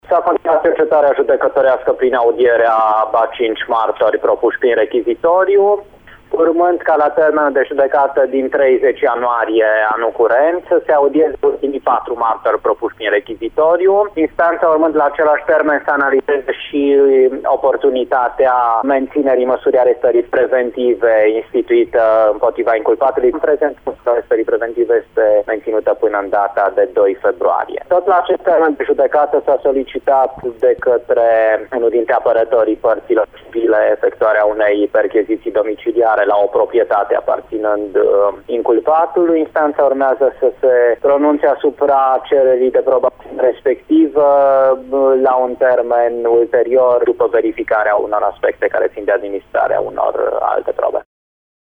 În 30 ianuarie urmează să fie audiaţi şi ultimii patru martori. Tot la aceeaşi dată se va decide şi menţinerea arestării preventive a inculpatului, în prezent această măsură fiind menţinută până în 2 februarie, a precizat preşedintele Tribunalului Caraş-Severin, judecător Rustin Ceasc: